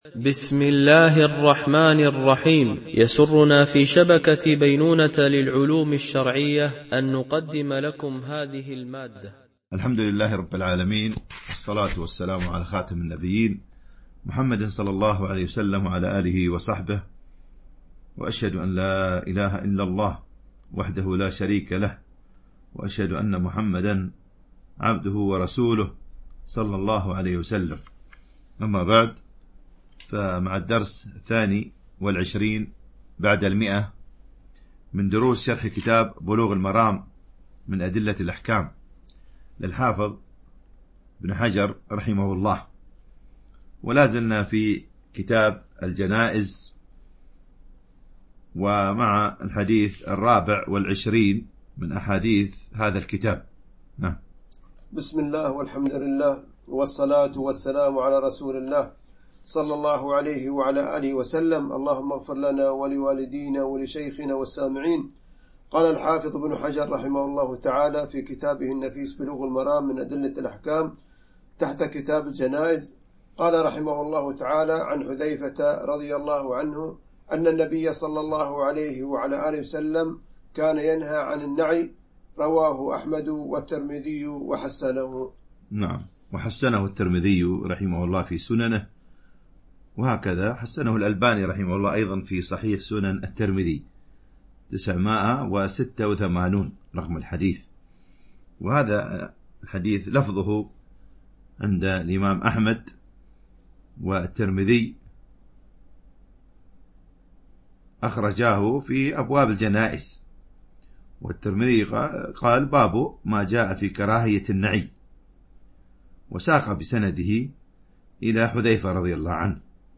شرح بلوغ المرام من أدلة الأحكام - الدرس 122 ( كتاب الجنائز - الحديث 557 - 559 )